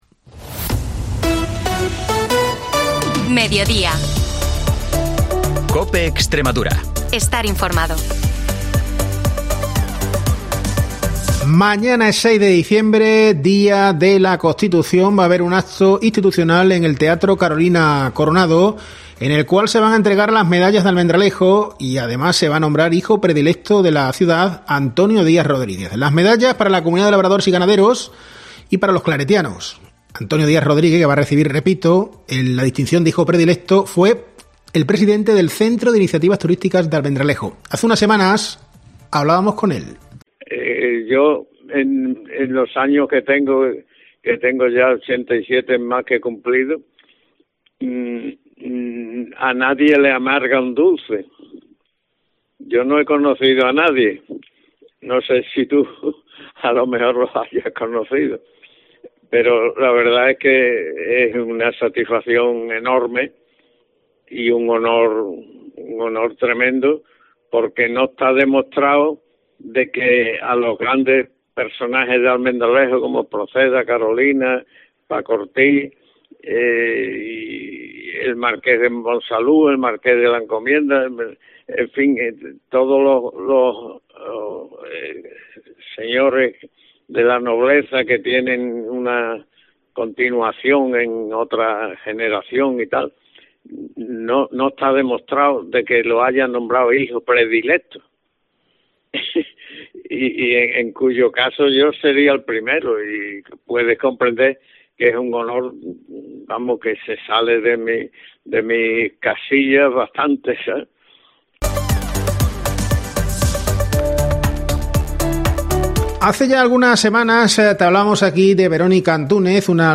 AUDIO: Información y entrevistas, de lunes a jueves, de 13.50 a 14 horas